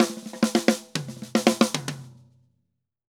Drum_Break 110_4.wav